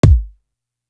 Big Twins Kick.wav